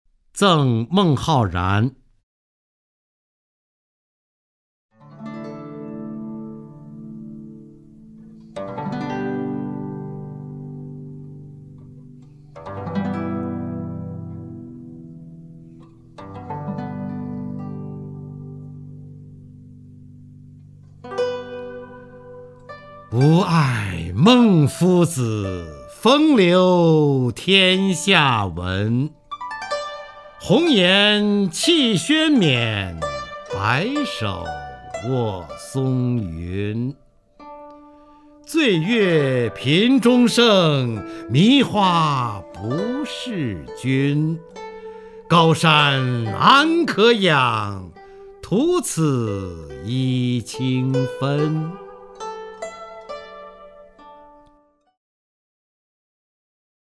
方明朗诵：《赠孟浩然》(（唐）李白) （唐）李白 名家朗诵欣赏方明 语文PLUS